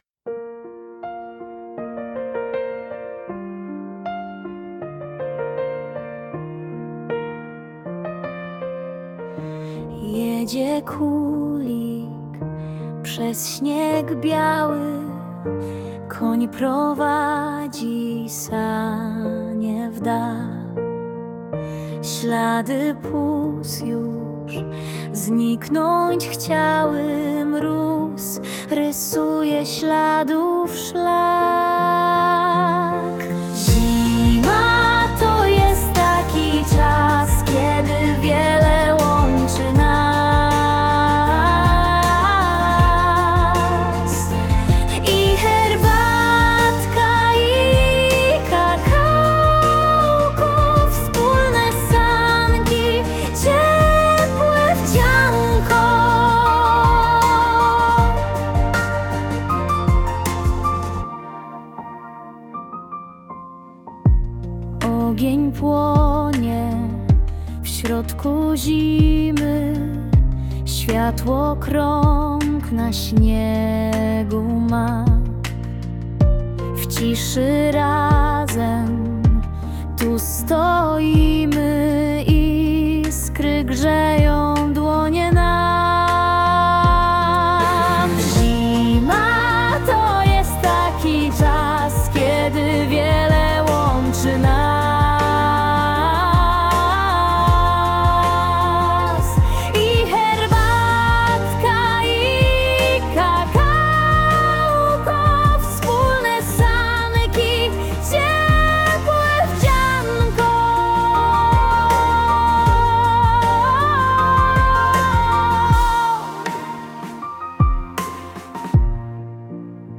Dzięki fantazji uczennicy Szkoły Podstawowej w Góralicach powstała zimowa piosenka, pełna śmiechu, śniegu i marzeń.